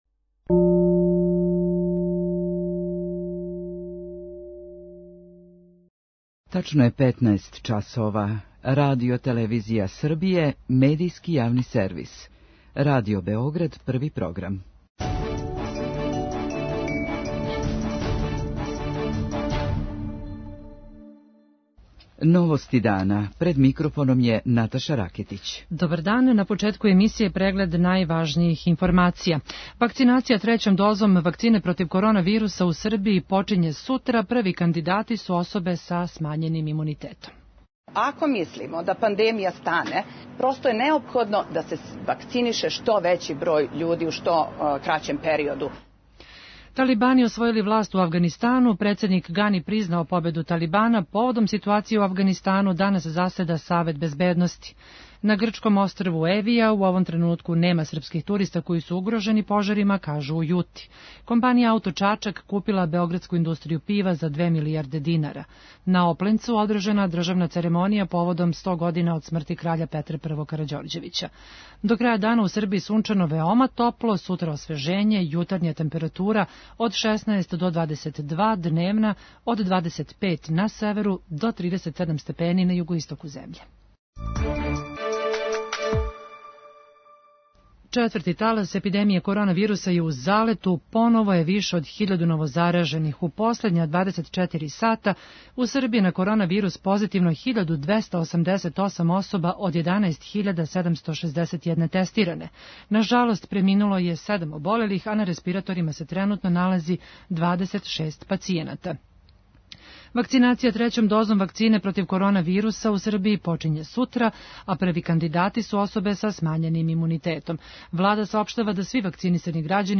Позивање грађана ће се обављати СМС-ом и имејлом који ће их преусмерити на Портал Е-управе. преузми : 6.17 MB Новости дана Autor: Радио Београд 1 “Новости дана”, централна информативна емисија Првог програма Радио Београда емитује се од јесени 1958. године.